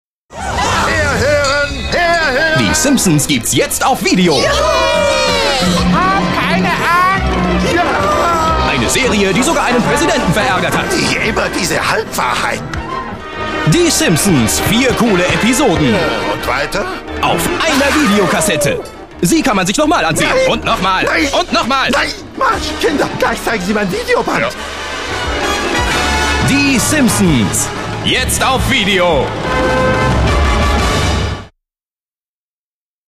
deutscher Synchronsprecher, Off-Stimme, Moderator, Werbesprecher, Hörspiel, Trickstimme, Dialekte
Sprechprobe: eLearning (Muttersprache):
german voice over artist